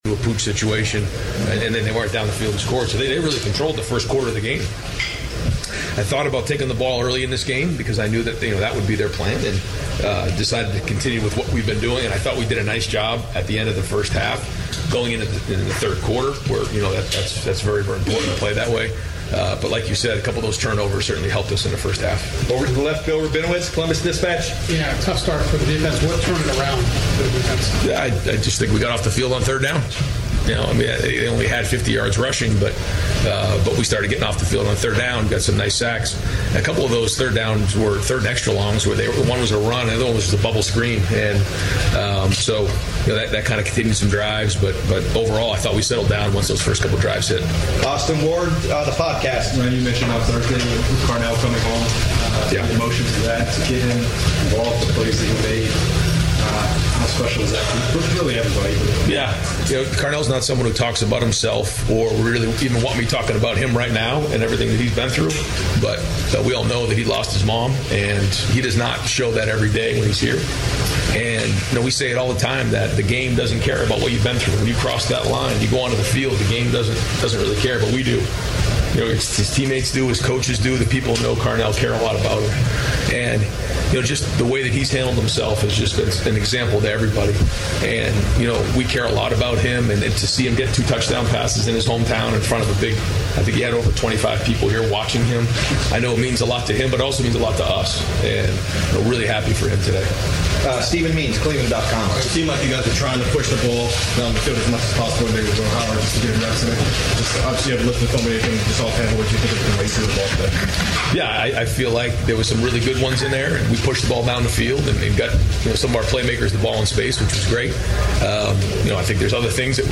#2 Ohio State beats scrappy Northwestern, 31-7 at Wrigley Field; Ryan Day Postgame Press Conference